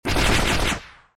가시 던지기 사운드 수정
GateOfSpikesSfx.mp3